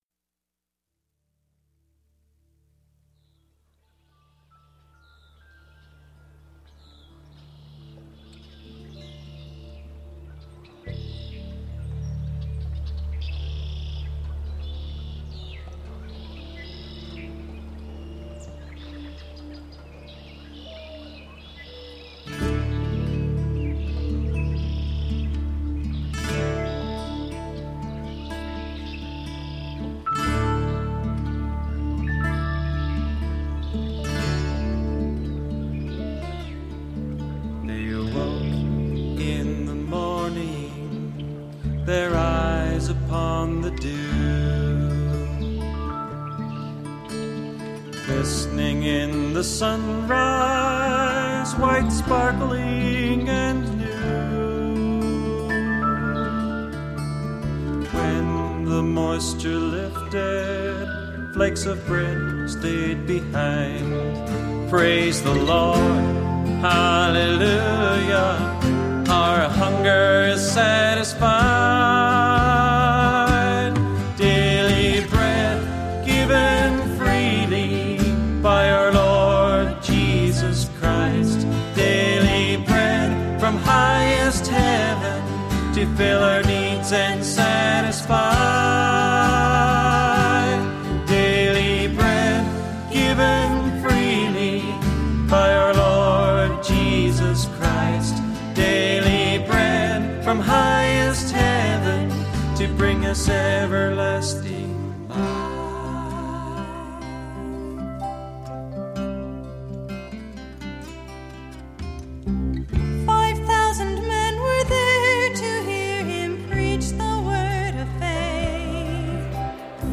Piano
Electric & acoustic guitars
Bass guitar
Percussion
Trumpet
English horn, saxophone and clarinet
Synthesizer
Background vocals